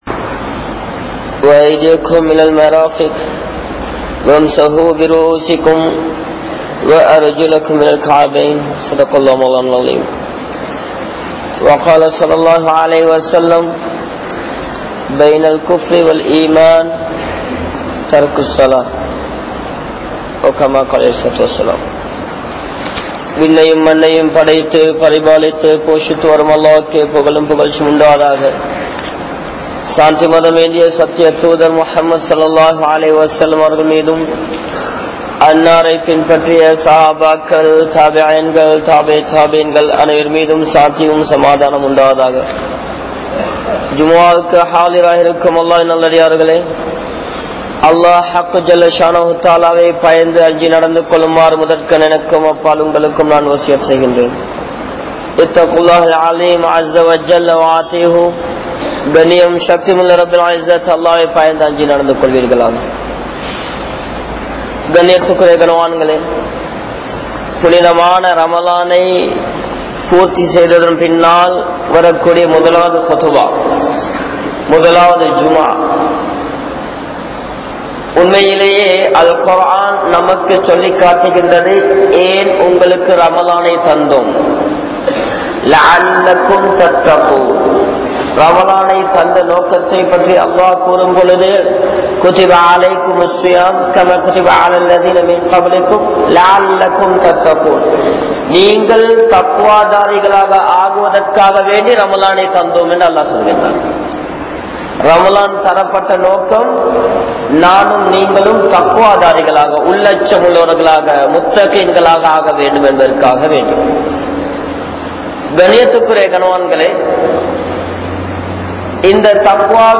Gaza`vin Indraya Nilamaium Media`vum | Audio Bayans | All Ceylon Muslim Youth Community | Addalaichenai
Katugasthoata Jumua Masjith